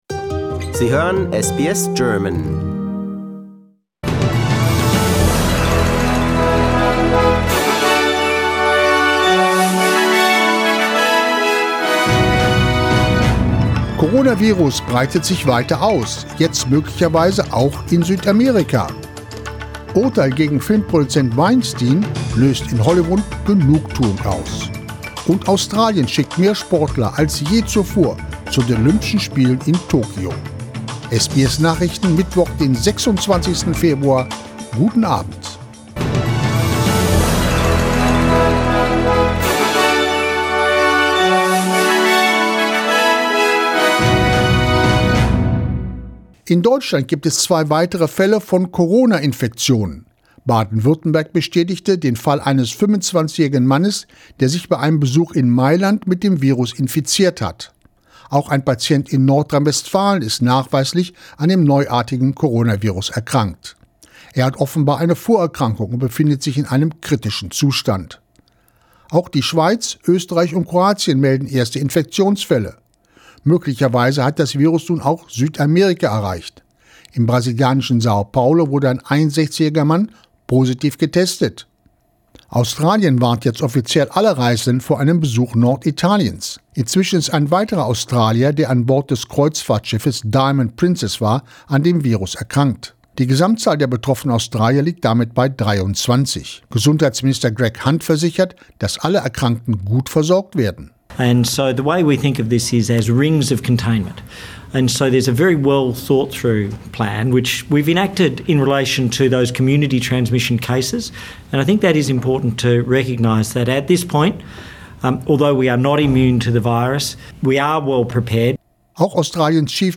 SBS Nachrichten, Mittwoch 26.02.20